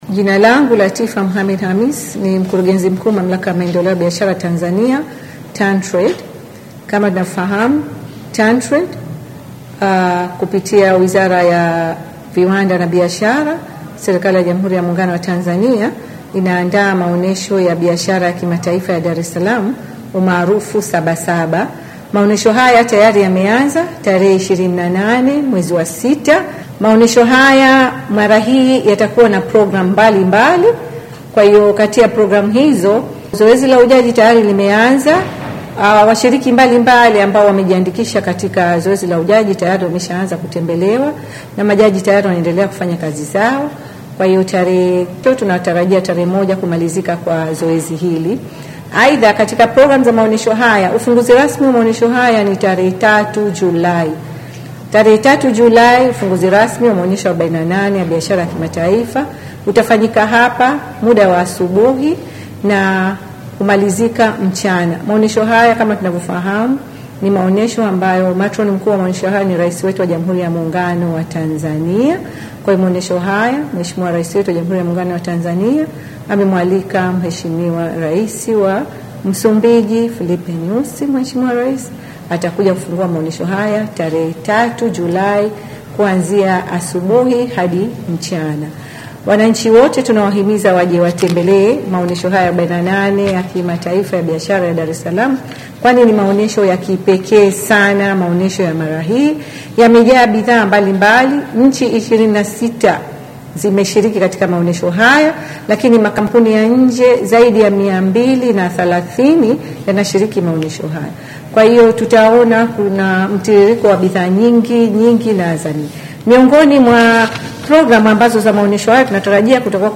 Mtaa wa mastory imenasa sauti ya Mkurugenzi Mkuu wa Mamlaka ya Maendeleo ya Biashara Tanzania (TanTrade) Latifa Khamis akieleza hayo ambapo amesema Rais Samia Suluhu Hassan ndiye ameidhinisha Rais huyo wa Msumbiji kufungua maonesho hayo.